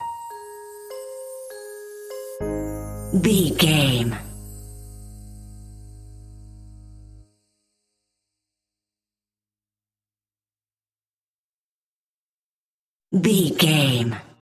Peaceful Electro Strings Stinger.
Aeolian/Minor
calm
electronic
synths